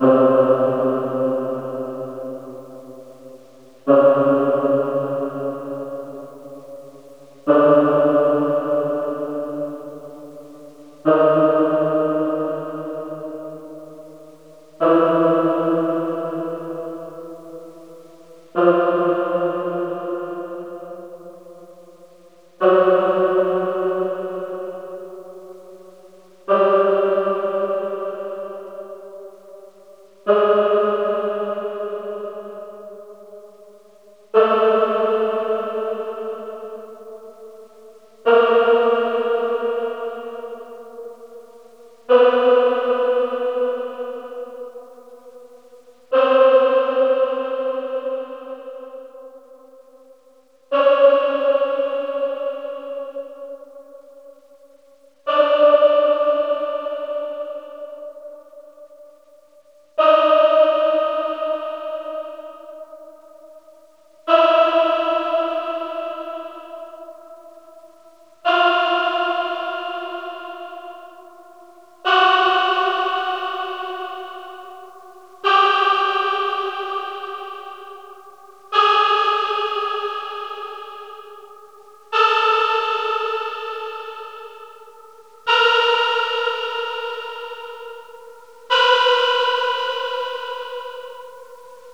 M Female Pad.wav